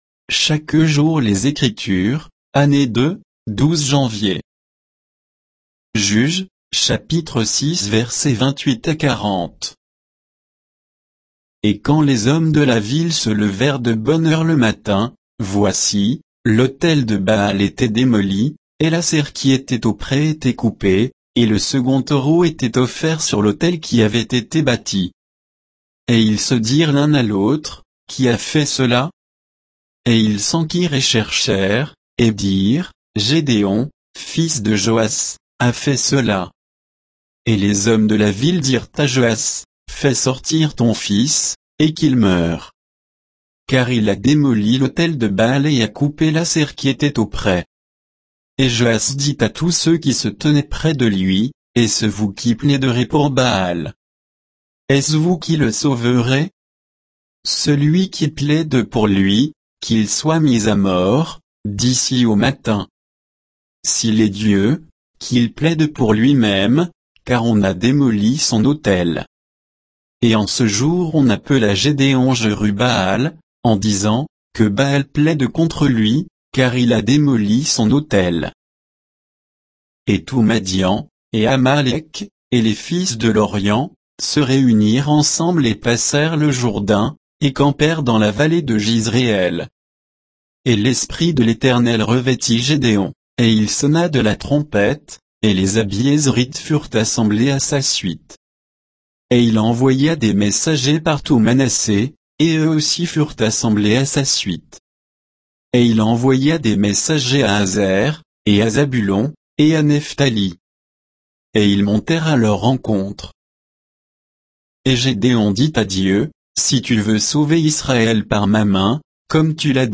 Méditation quoditienne de Chaque jour les Écritures sur Juges 6